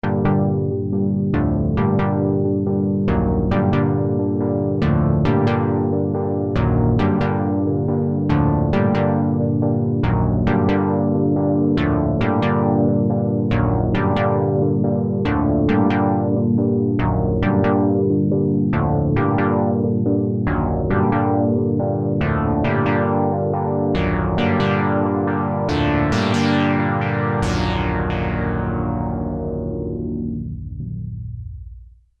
RS6 – Dub Stab | Supercritical Synthesizers
RS6-Dub-Stab.mp3